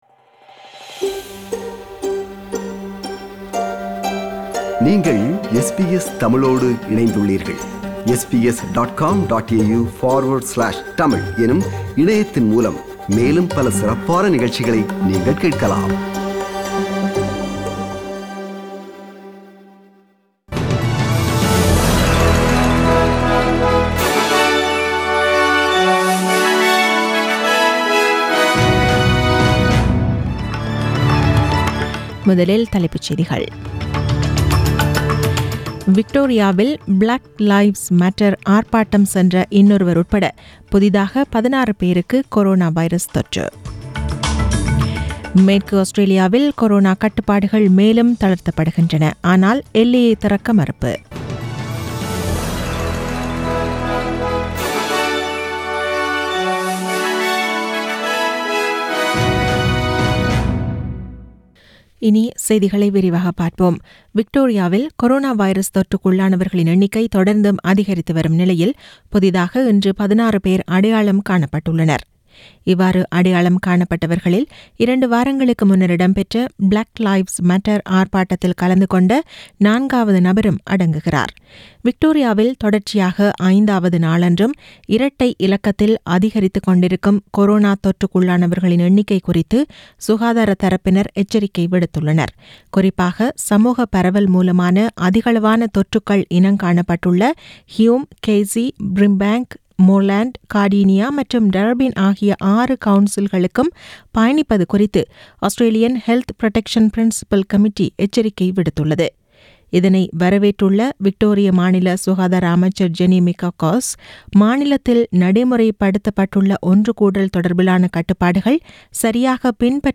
The news bulletin was aired on 22 June 2020 (Monday) at 8pm.